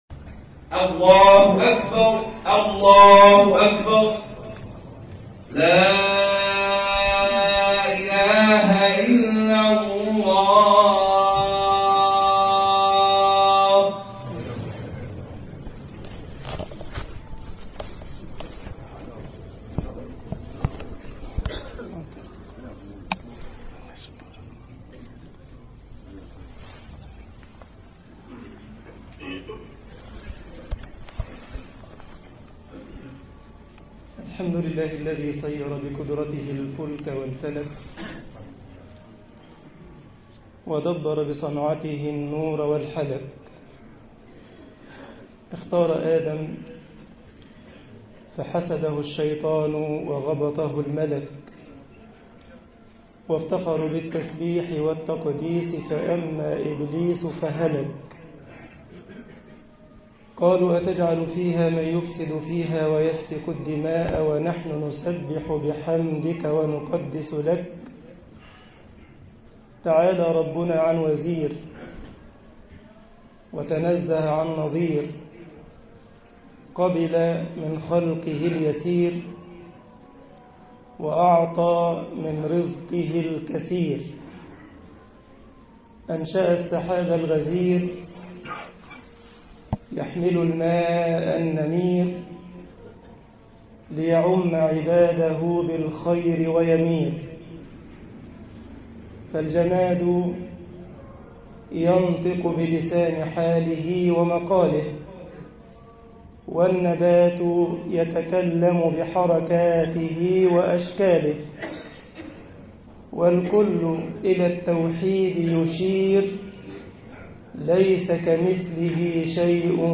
الاناء المنكوس لن يطهر كثرة النجاسة فيه الانهار و البحار لو افيضت عليه طباعة البريد الإلكتروني التفاصيل كتب بواسطة: admin المجموعة: مواضيع مختلفة Download مصر خطبة جمعة 20 يناير 2012 م التفاصيل نشر بتاريخ: الخميس، 08 آذار/مارس 2012 14:54 الزيارات: 2308 السابق التالي